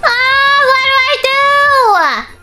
Worms speechbanks
Bummer.wav